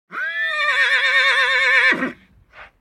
دانلود صدای اسب 13 از ساعد نیوز با لینک مستقیم و کیفیت بالا
جلوه های صوتی
برچسب: دانلود آهنگ های افکت صوتی انسان و موجودات زنده دانلود آلبوم صدای انواع اسب از افکت صوتی انسان و موجودات زنده